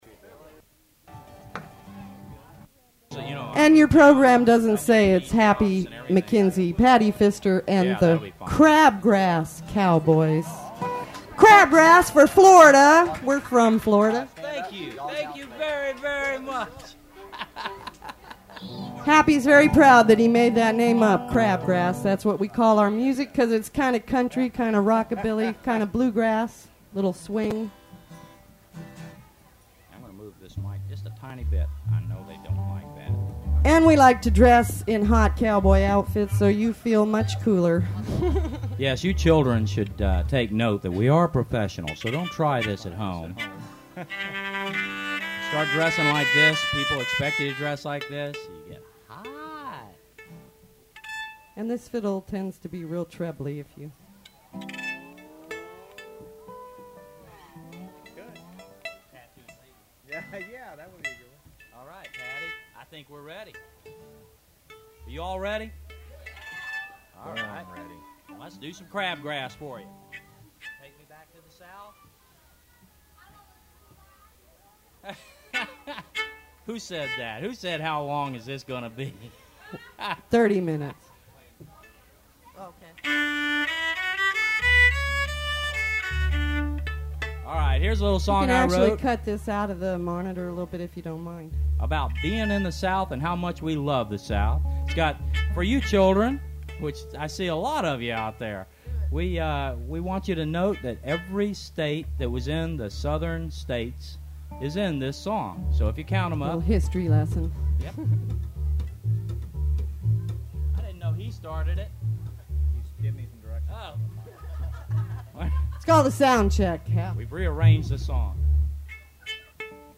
Florida Memory • Friday performances at the 1995 Florida Folk Festival (Stage III) (Tape 3)
One audio cassette.